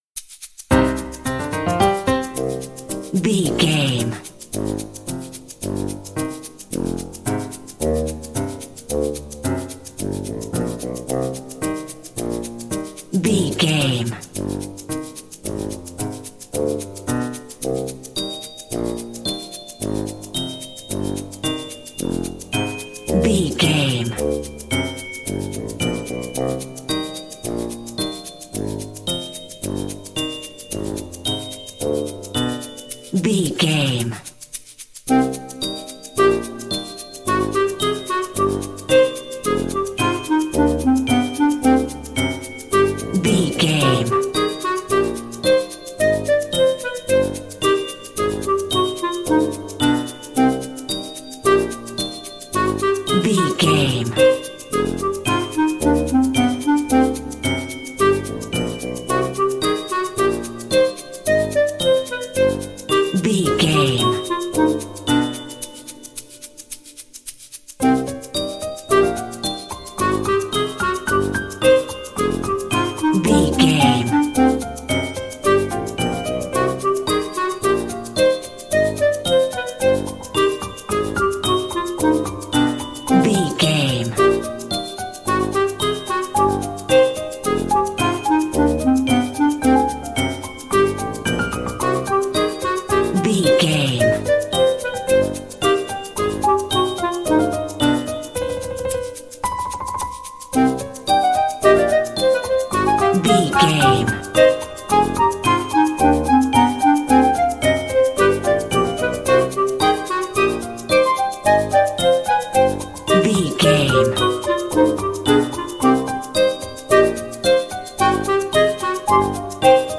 Aeolian/Minor
orchestra
piano
percussion
horns
silly
circus
goofy
comical
cheerful
perky
Light hearted
quirky